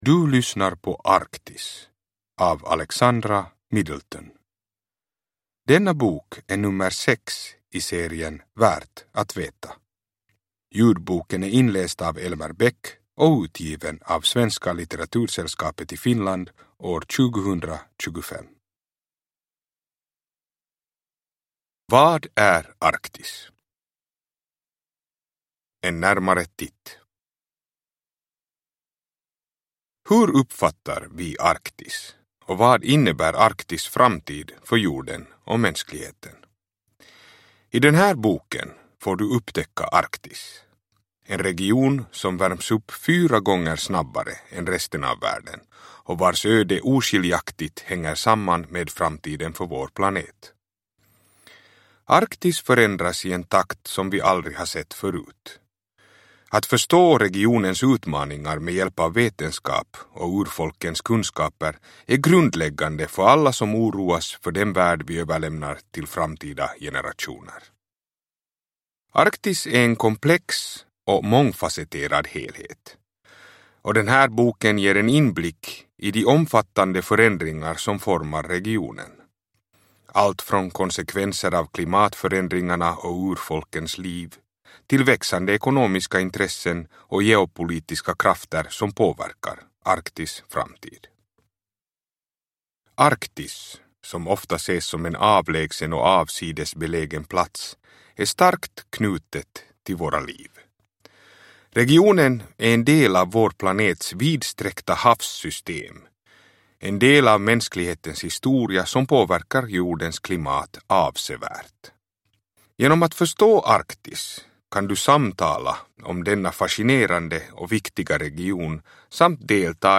Arktis – Ljudbok